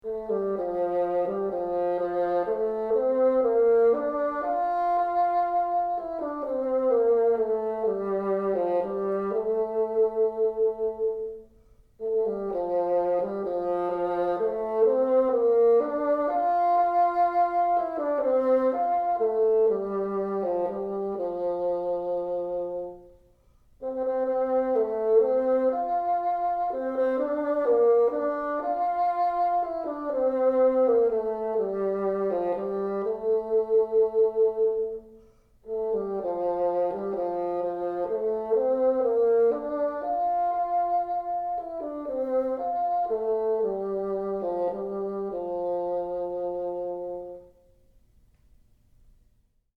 Can you use vibrato on each half note in the duet below?